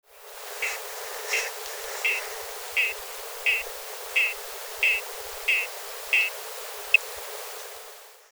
Play Especie: Boophis laurenti Género: Boophis Familia: Mantellidae Órden: Anura Clase: Amphibia Título: The calls of the frogs of Madagascar.
Localidad: Madagascar: Cuvette Boby, Parque Nacional Andringitra Observaciones: Las frecuencias por debajo de 400 Hz han sido filtradas
52 Boophis Laurenti.mp3